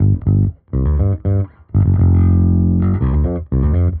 Index of /musicradar/dusty-funk-samples/Bass/120bpm
DF_JaBass_120-G.wav